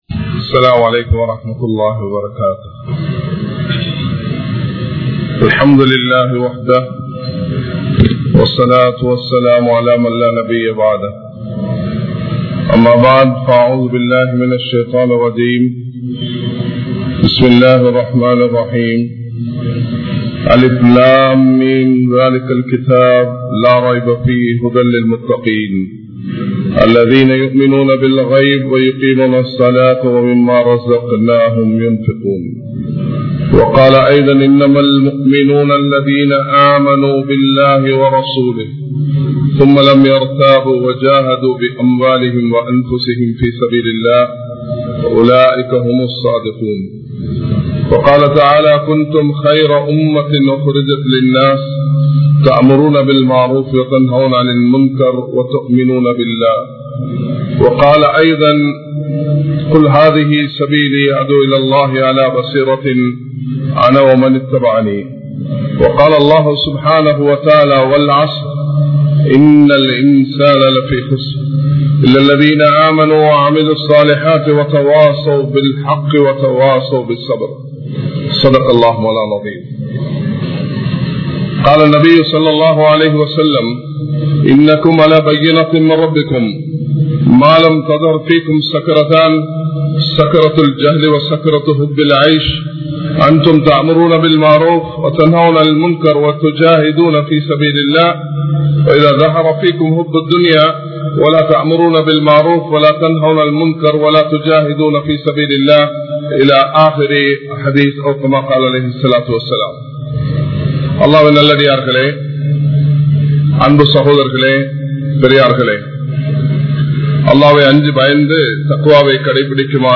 Nabi Maarhalullu Eatpatta Soathanaihal (நபிமார்களுக்கு ஏற்பட்ட சோதனைகள்) | Audio Bayans | All Ceylon Muslim Youth Community | Addalaichenai
Majma Ul Khairah Jumua Masjith (Nimal Road)